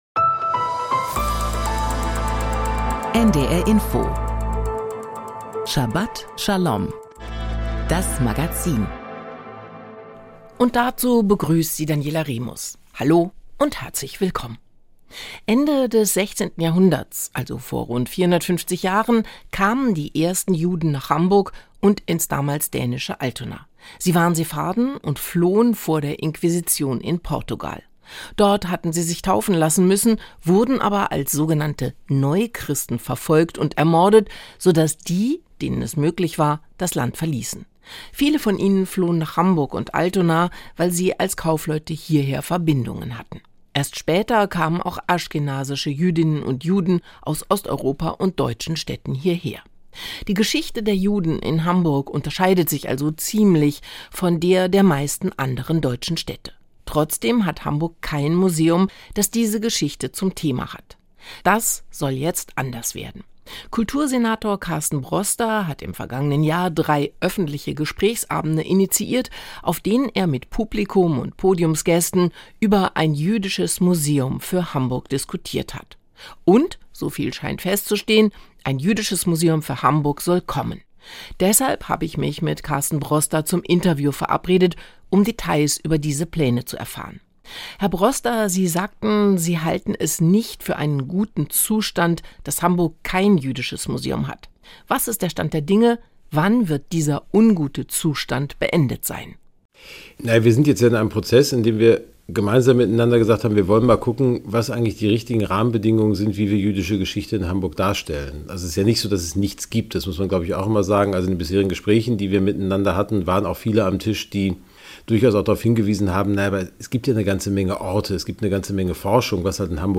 Thora-Auslegung